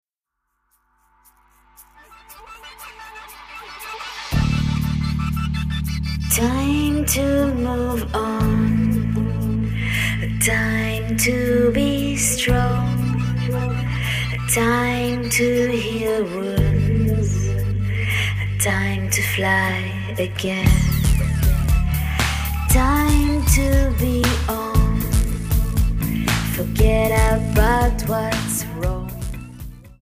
Alternative,Dance,Rock